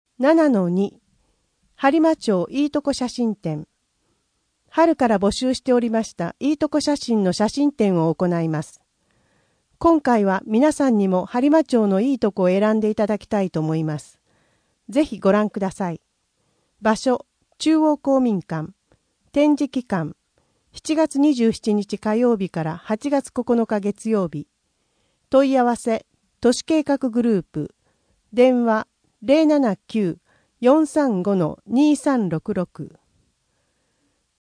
声の「広報はりま」7月号
声の「広報はりま」はボランティアグループ「のぎく」のご協力により作成されています。